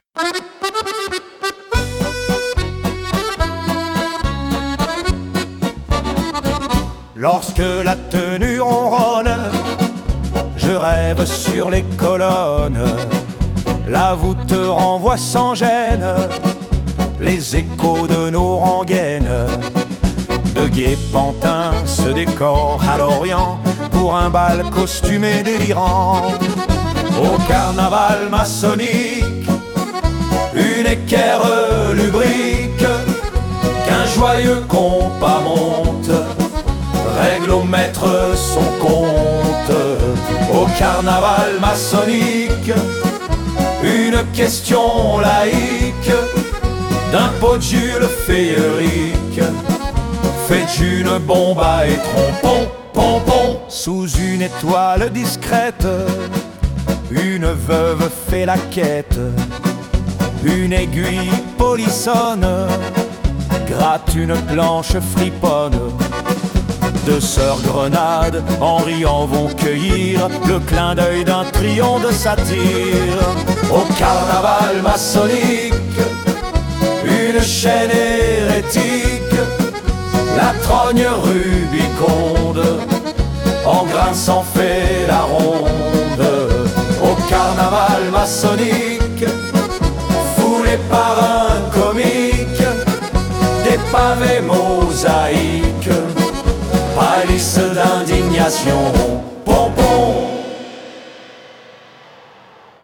Sur l’air de valse